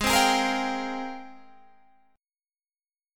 AbM13 Chord